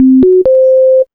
1706L BLEEPS.wav